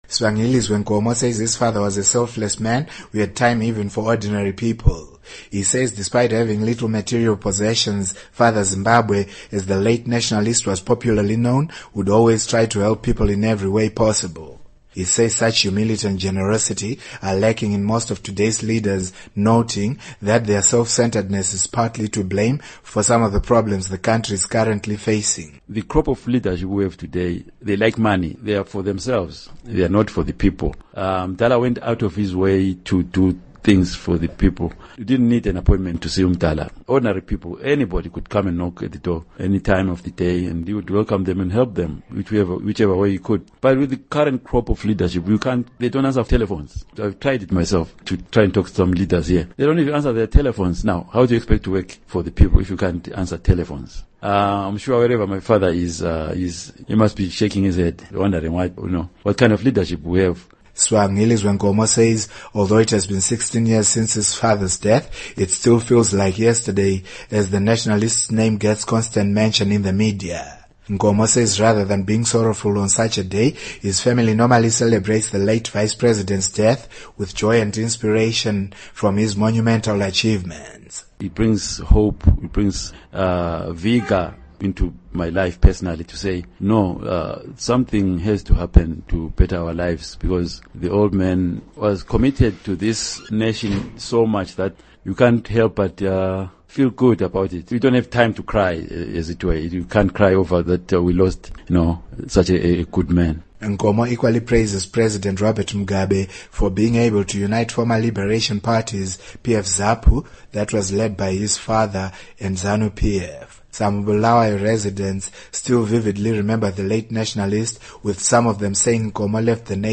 Report on Joshua Nkomo's Legacy